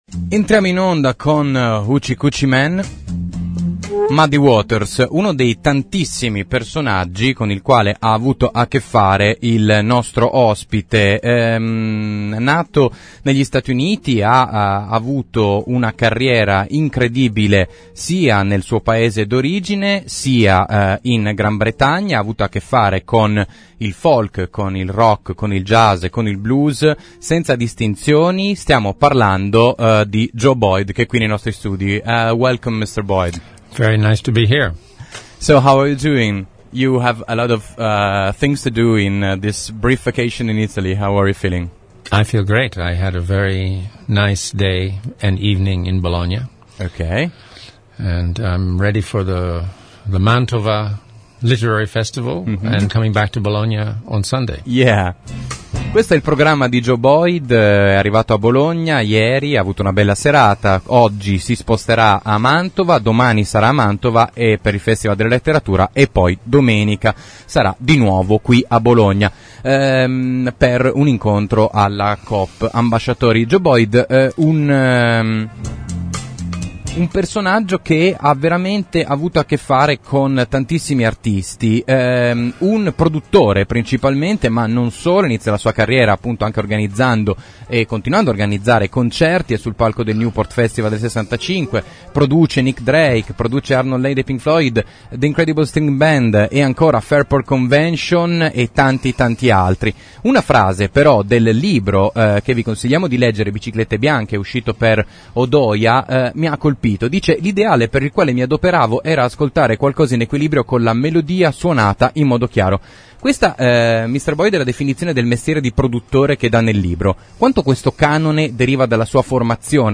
In occasione della presentazione del suo libro Biciclette bianche, il produttore Joe Boyd è passato in Italia: prima di essere ospite al Festival Letteratura di Mantova e di presenziare a un incontro con il pubblico bolognese, Joe è passato nei nostri studi per una lunga intervista in “Aperto per ferie”. Ascoltatela, o riascoltatela: si parla di Syd Barrett, Nick Drake, Bob Dylan, REM, Muddy Waters e Pink Floyd, solo alcuni degli artisti con cui Boyd ha lavorato.
Joe-Boyd-Intervista64.mp3